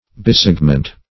Search Result for " bisegment" : The Collaborative International Dictionary of English v.0.48: Bisegment \Bi*seg"ment\, n. [Pref. bi- + segment.] One of tow equal parts of a line, or other magnitude.